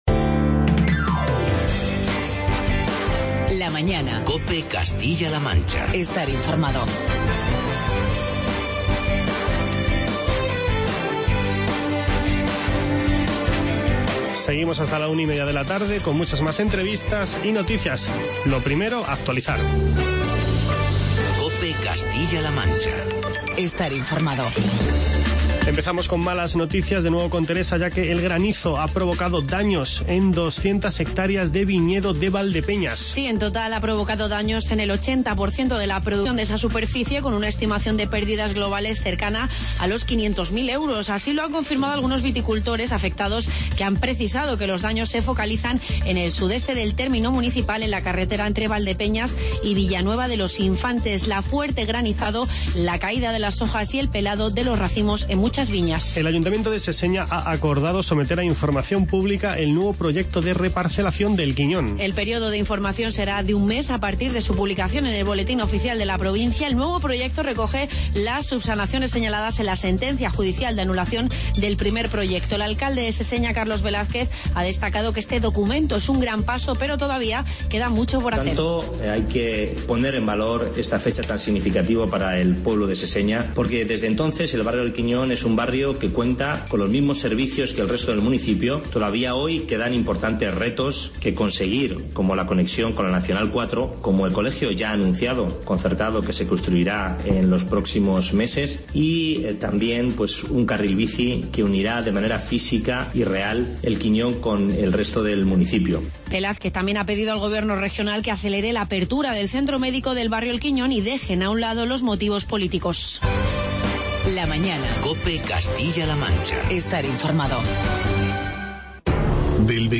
Charlamos con el cantante Mikel Erentxun y con el alcalde de la localidad toledana de Quismondo, José Eugenio del Castillo.